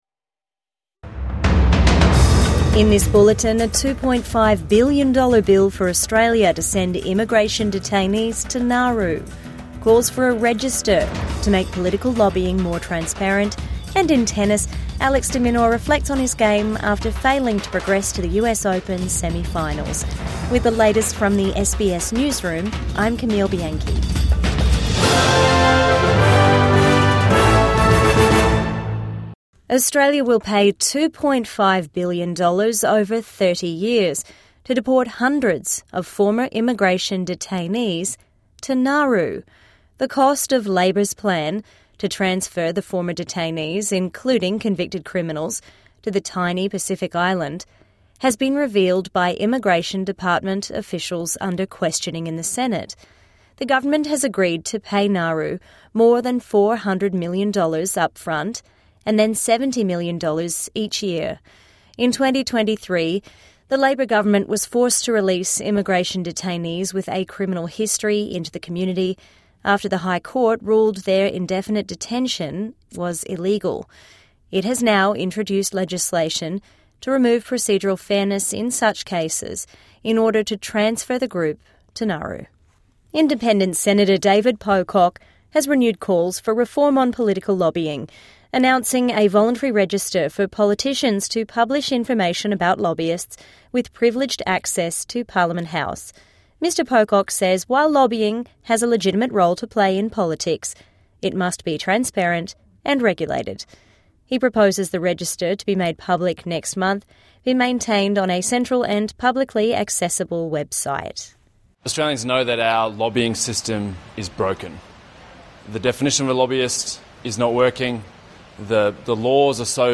$2.5 billion plan to house former immigration detainees| Midday News Bulletin 4 September 2025 6:01